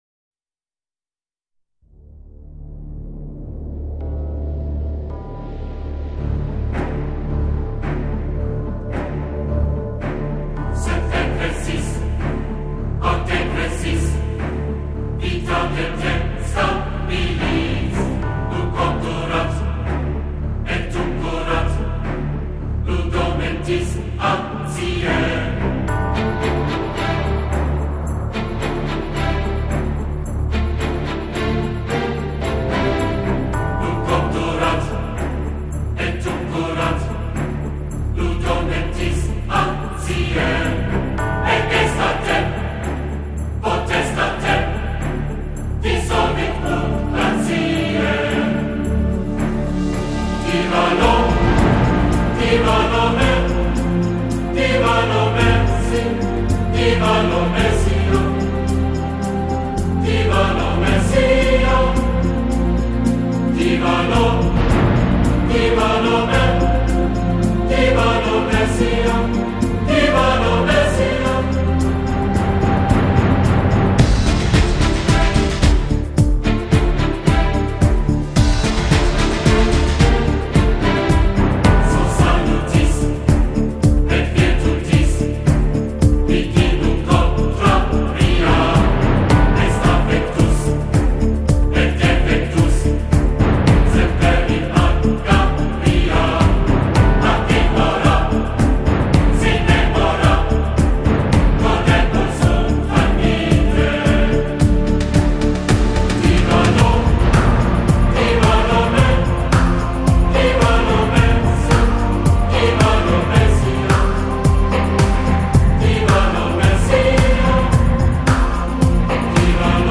现场感关键就在于音场，360度的音场感觉和普通唱片的小于180度的音场感觉，差异明显。